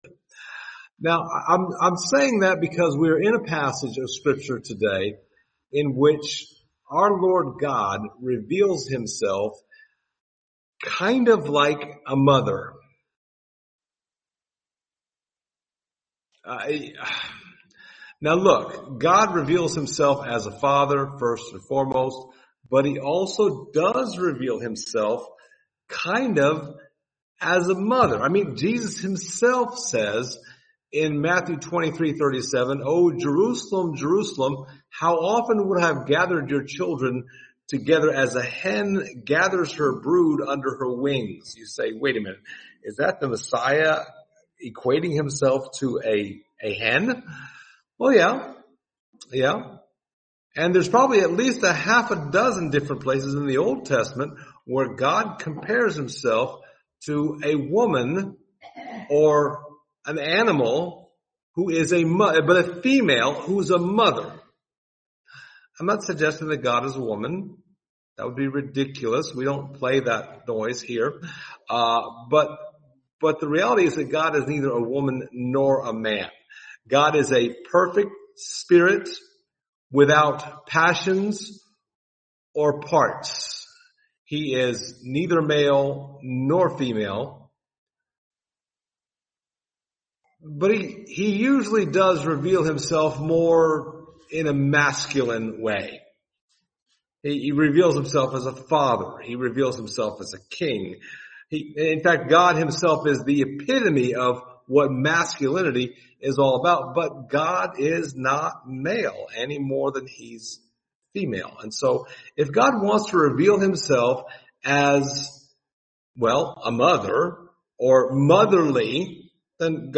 Passage: Psalm 131 Service Type: Sunday Morning Topics